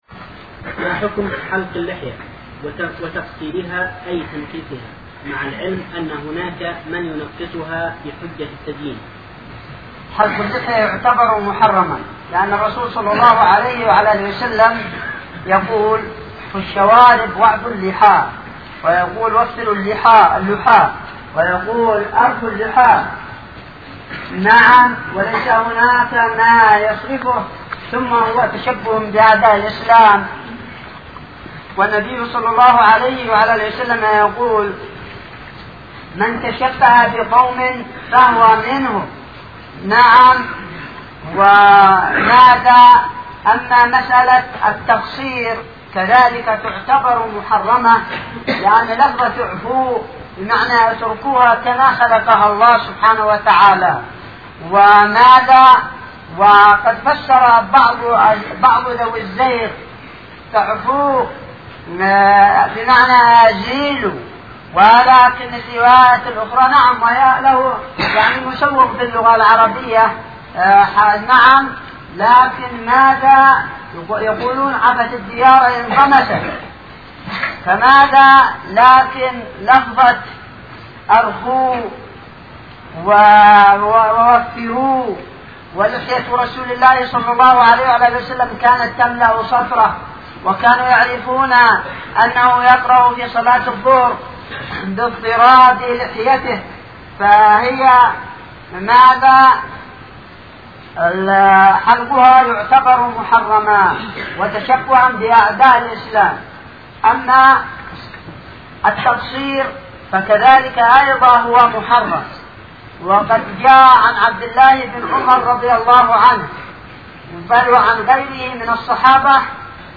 ما حكم حلق اللحية وتنقصيرها ؟ | فتاوى الشيخ مقبل بن هادي الوادعي رحمه الله